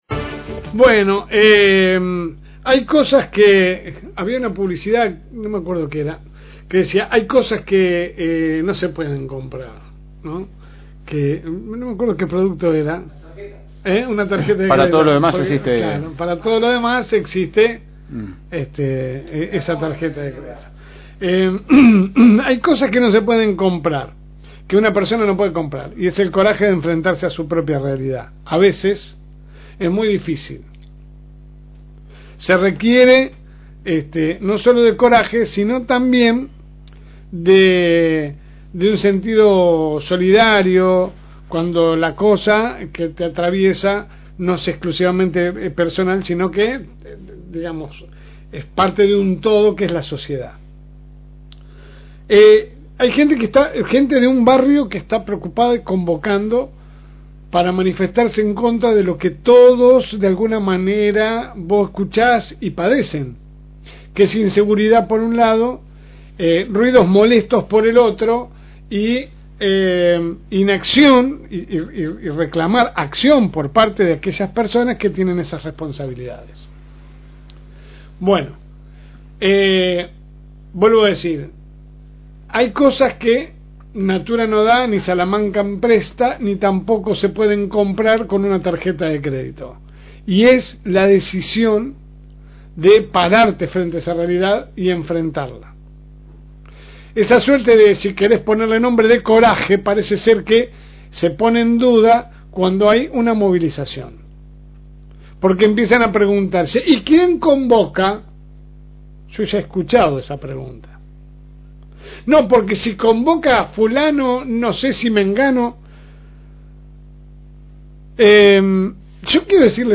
AUDIO – Editorial de la LSM. – FM Reencuentro